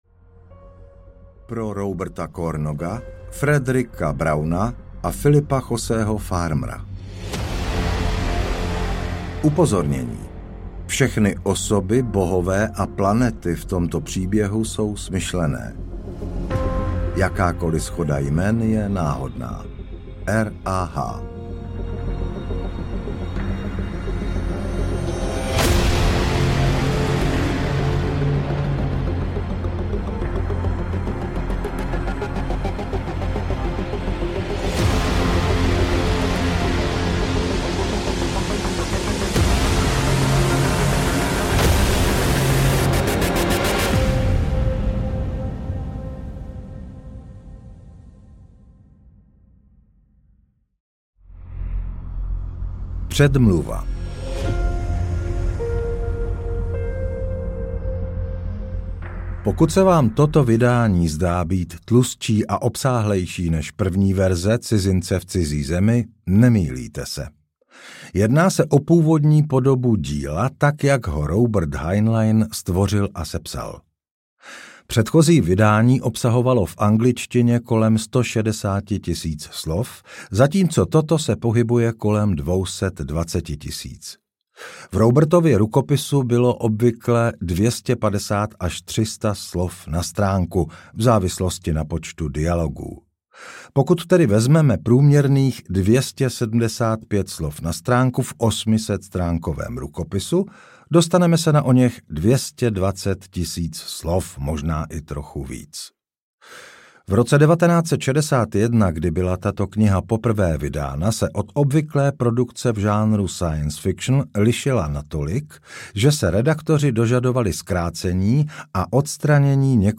Cizinec v cizí zemi audiokniha
Původní nezkrácená verze – poprvé v České republice!
Ukázka z knihy